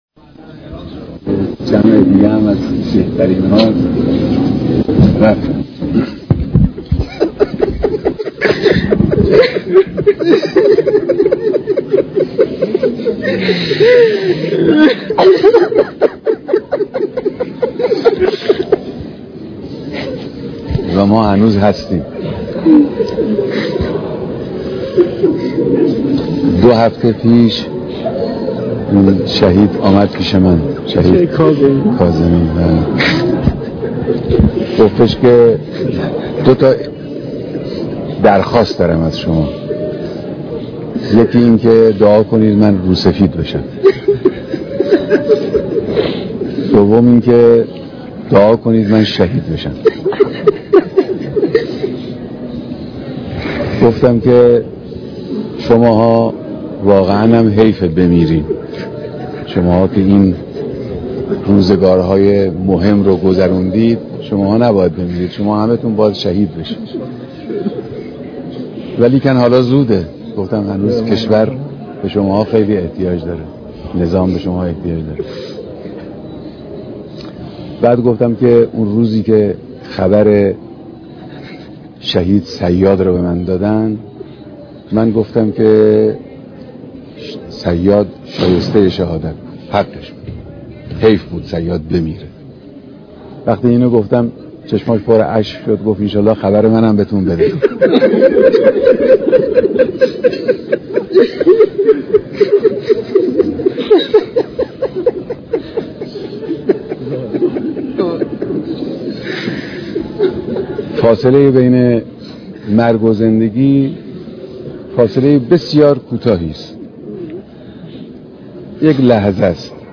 مراسم تشييع پيكر شهيد احمد كاظمي و تعدادي از سرداران سپاه